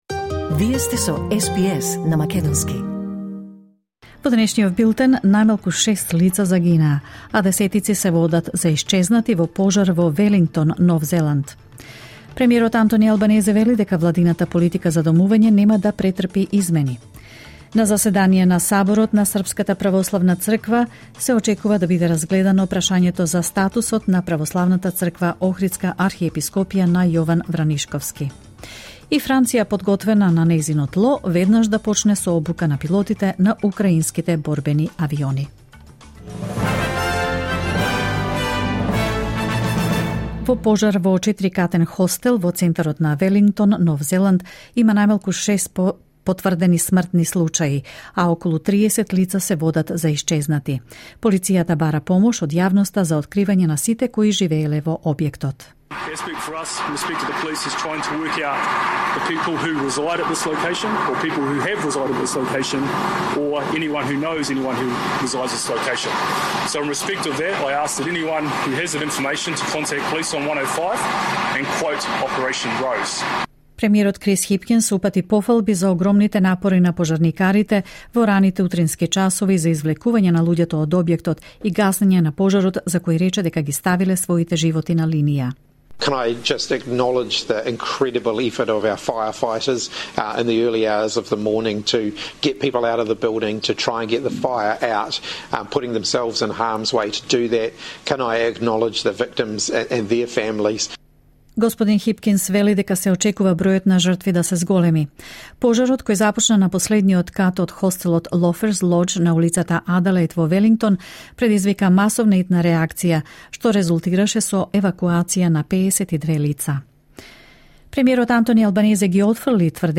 SBS News in Macedonian 16 May 2023